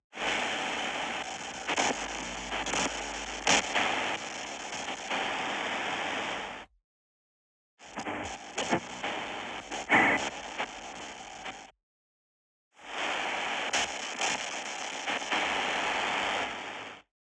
Static_Small_Radio_Searching_Channels_Soft_01.wav